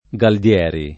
[ g ald L$ ri ]